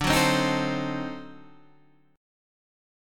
D 7th Flat 9th